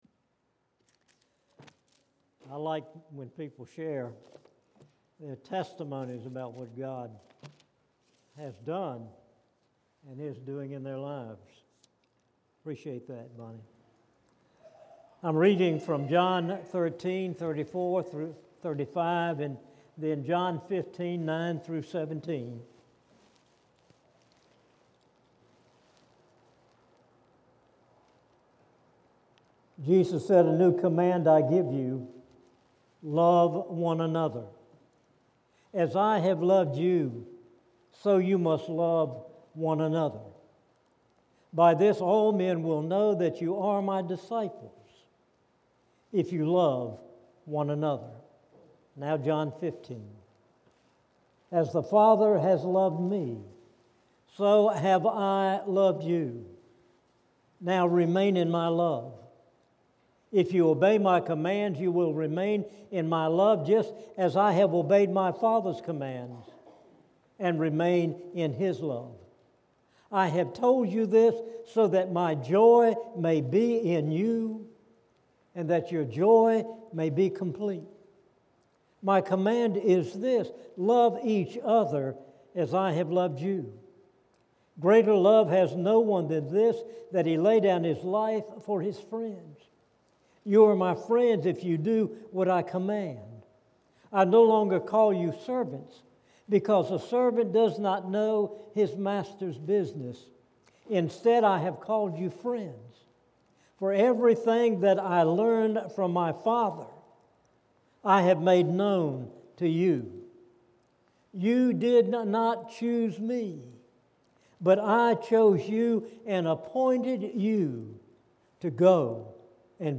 Tramway Baptist Church Sermons